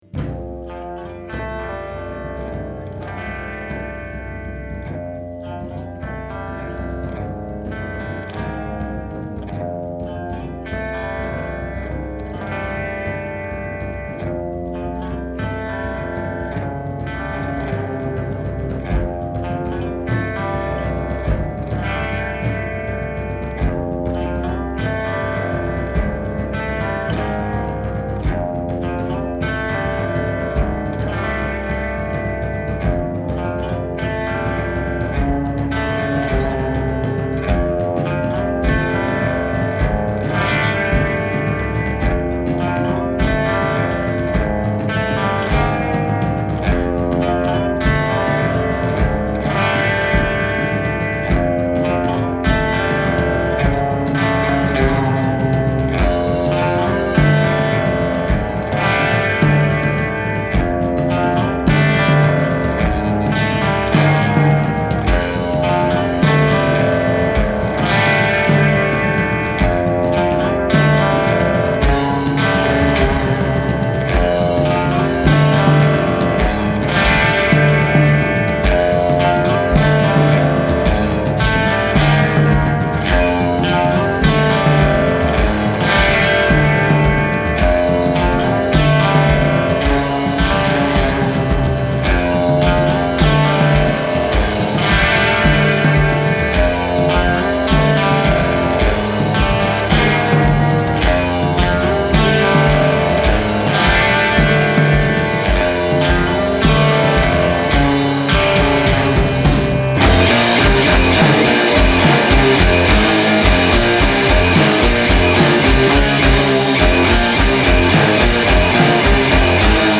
stoner-punk
guitar / drums
the result is a tidal wave imbued with deep sounds.